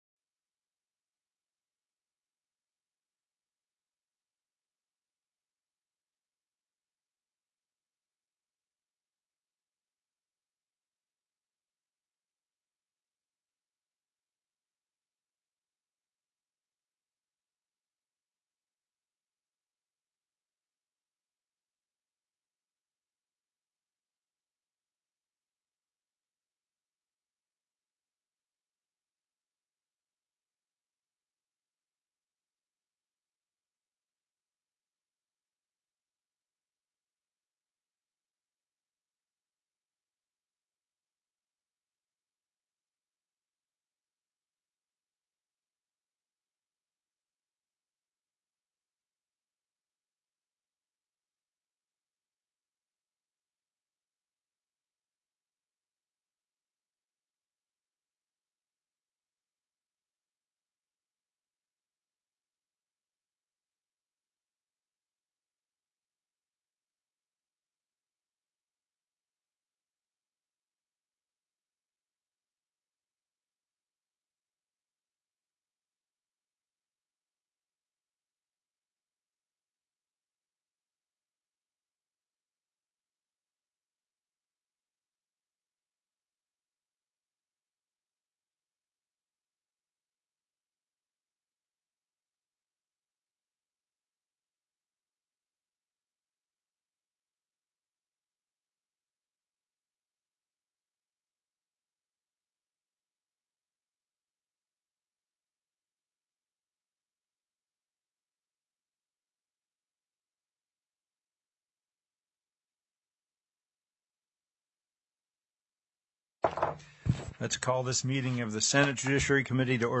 The audio recordings are captured by our records offices as the official record of the meeting and will have more accurate timestamps.
SB 249 VIRTUAL CURRENCY KIOSKS TELECONFERENCED Heard & Held -- Invited & Public Testimony -- Bills Previously Heard/Scheduled